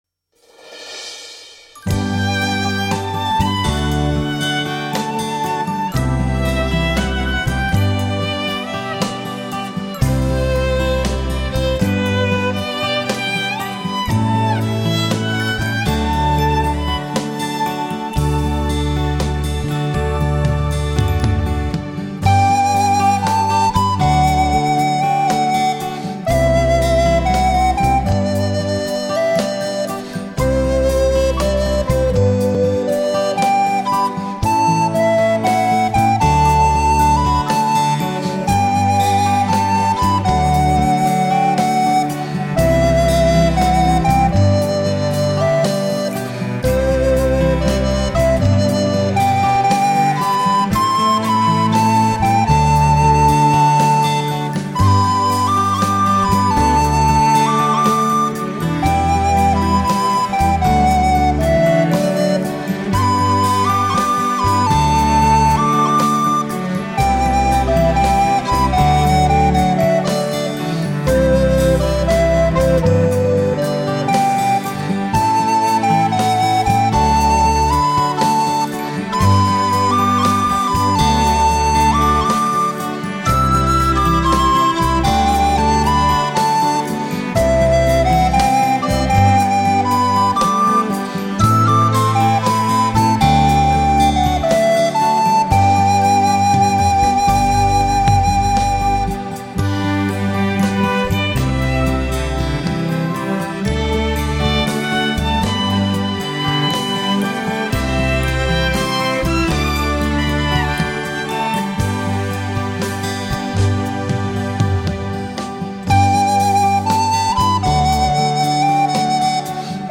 新世紀的編曲，優美的曲調，經典的組合，賦予傳統直笛一種新的靈魂和意境。